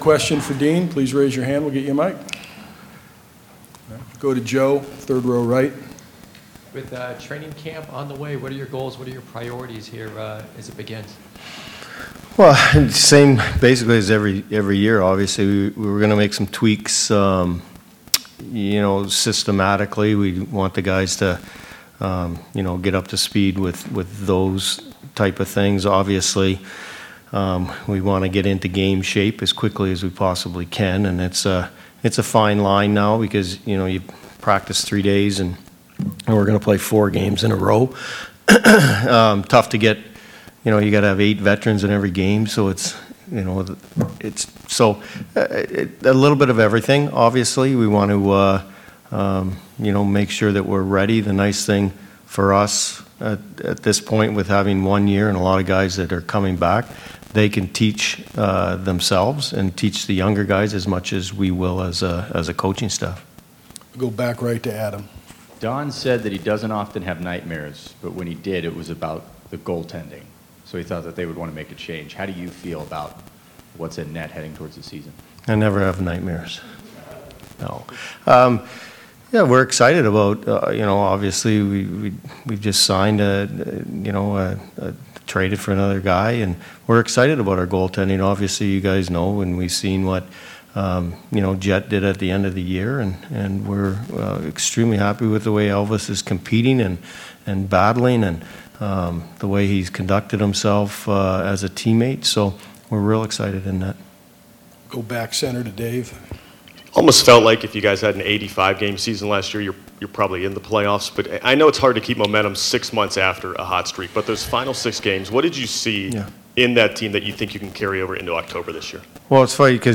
Blue Jackets exhibition season moving quickly; playing 2nd game of 4 in a row tonight against Buffalo, 7 PM; head coach Dean Evason talks new season and reflects on memorable 2024-25 season